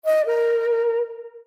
Calligraphy Notify